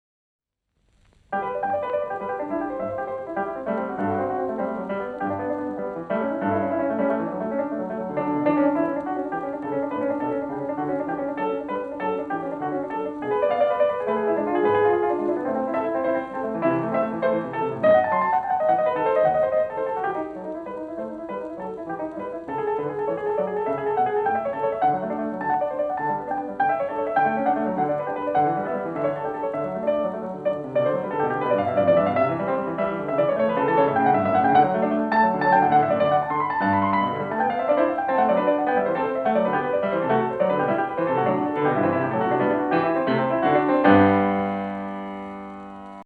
A -
Bach, The Well-Tempered Clavier, Book I, Prelude in G-Major